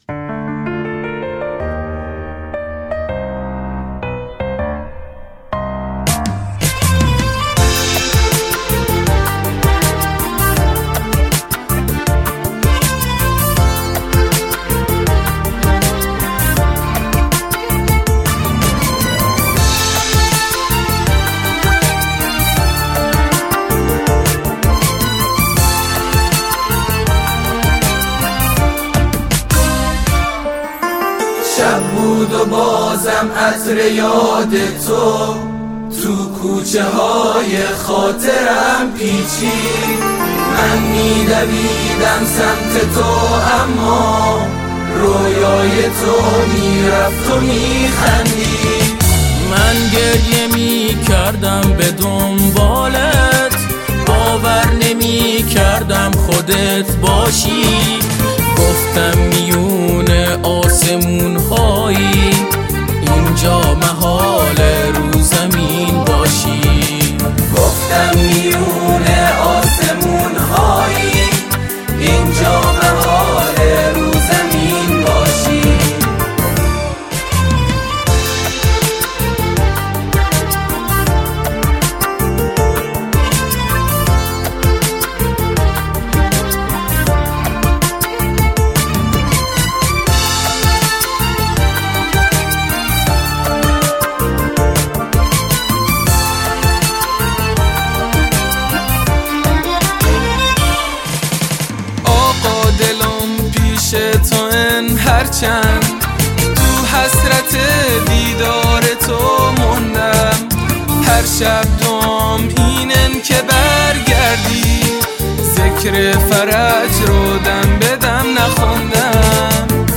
کاری از جوانان و نوجوانان